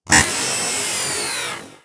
bat_die.wav